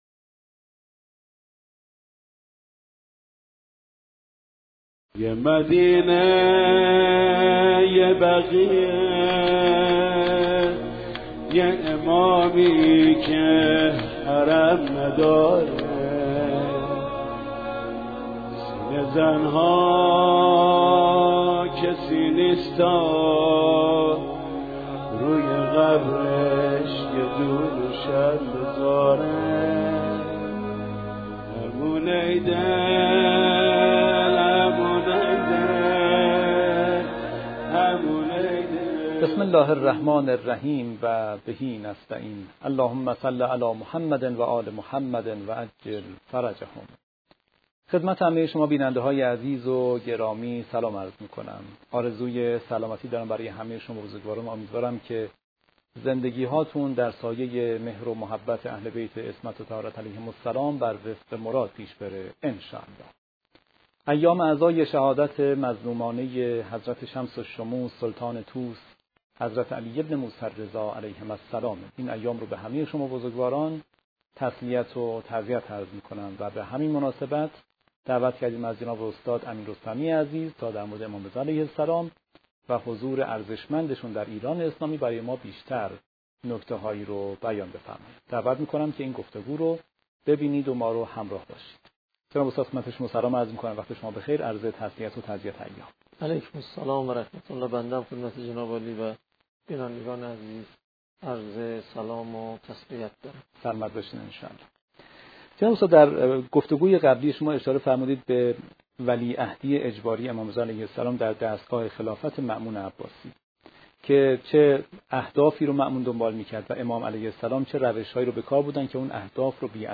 این گفتگو به مناسبت ایام شهادت امام رضا(ع) و با هدف بررسی اهداف مأمون از ولیعهدی اجباری و به ویژه تبیین آثار و برکات حضور ایشان در ایران انجام شده است.